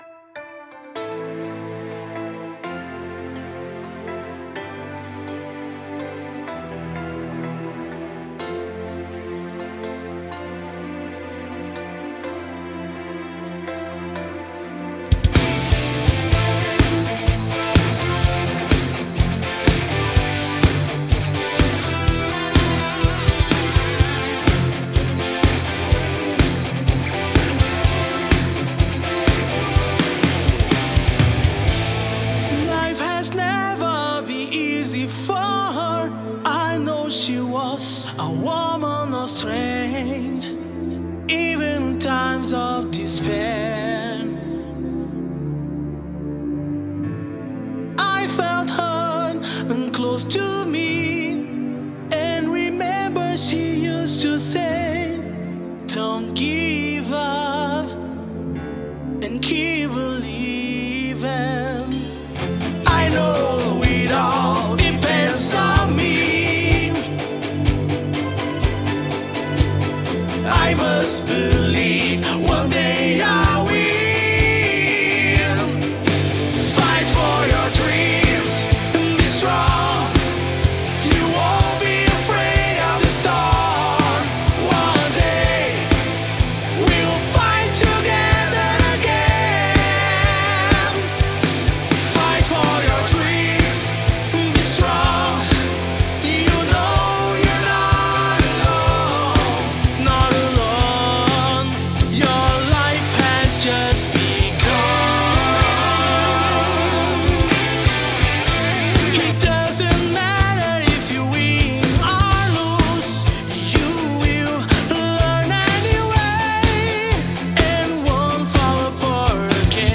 Жанр: Melodic Rock, AOR